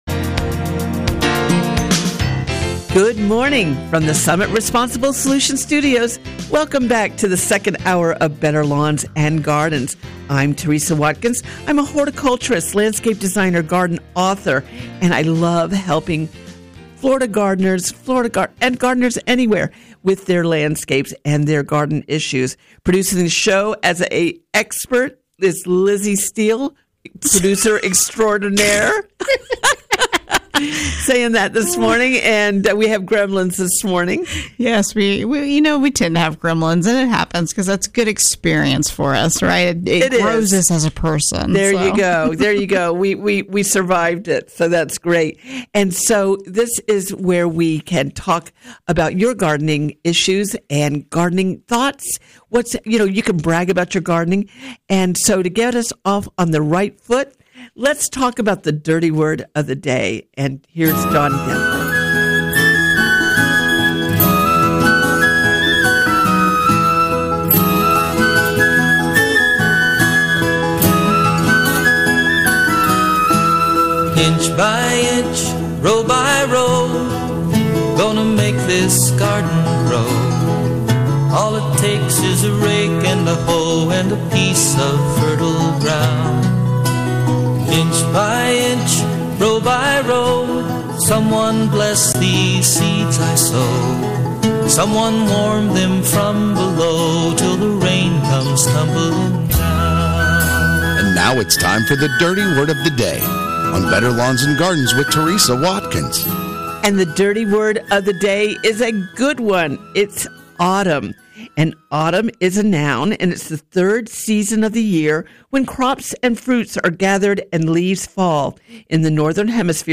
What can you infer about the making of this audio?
Coming to you from Summit Responsible Solutions Studios